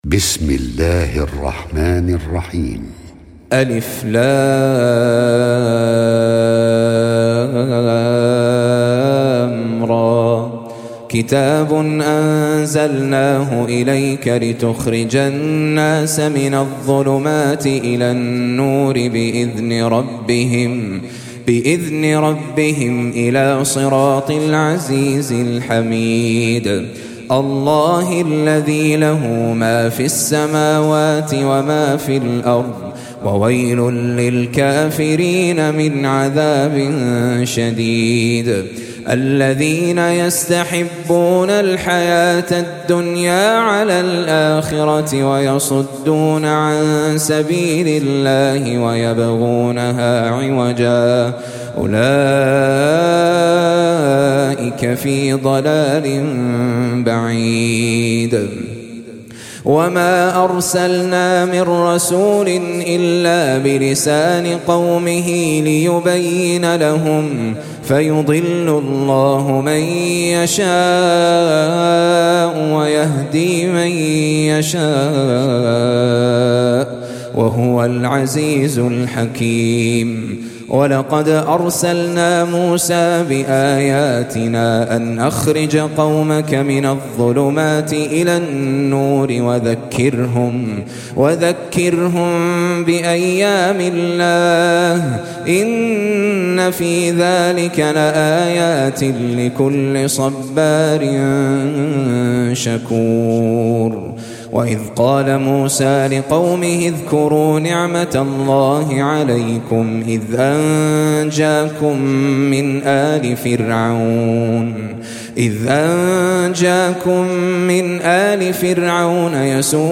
14. Surah Ibrah�m سورة إبراهيم Audio Quran Tajweed Recitation
Surah Sequence تتابع السورة Download Surah حمّل السورة Reciting Murattalah Audio for 14.